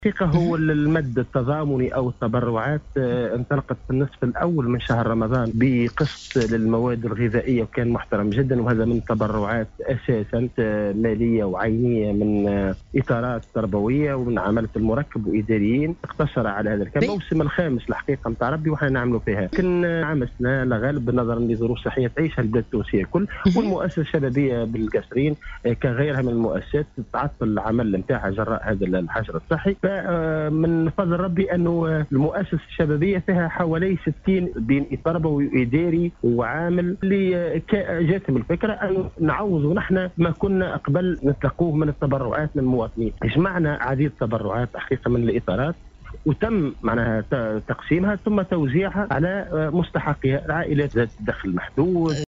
في اتصال باذاعة السيليوم أف أم